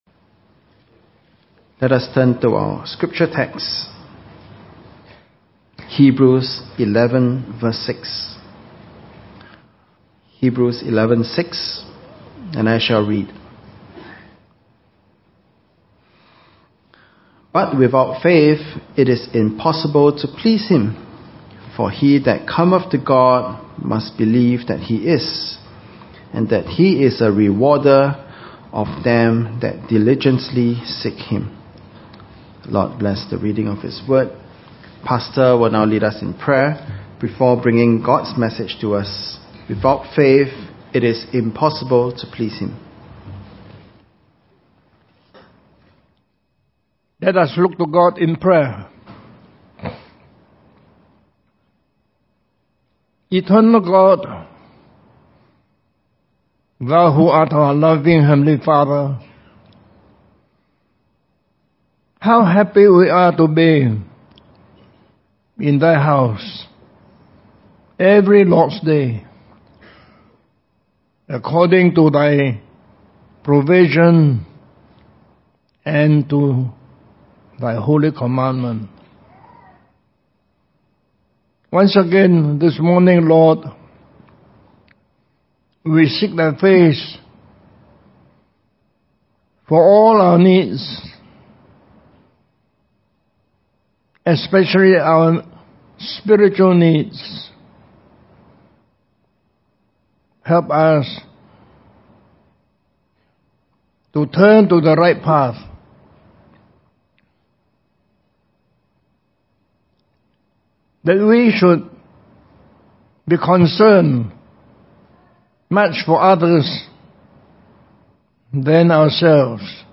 In this sermon, the preacher emphasizes the importance of faith in salvation. He references John 3:16, which states that whoever believes in Jesus will have everlasting life.